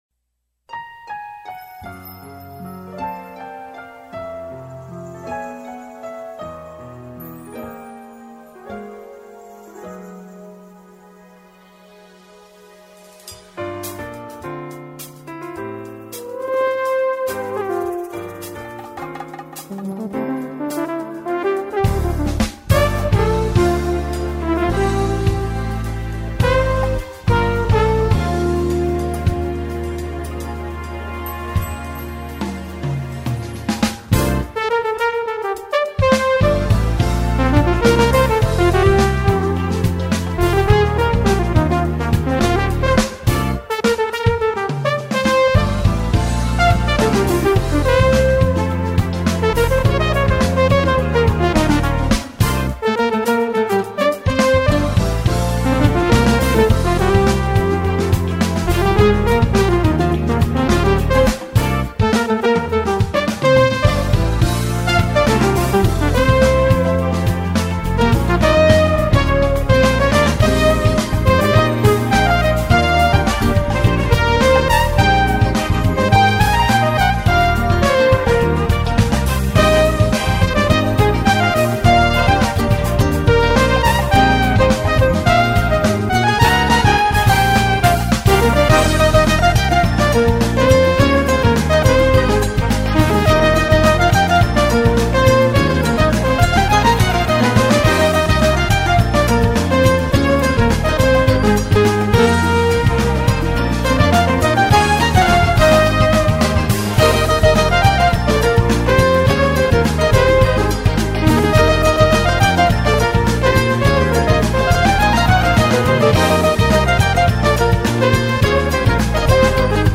와~ ^.^ 멜로디가 넘 이뻐서 좋아하는 곡인데 정말 근사한데요.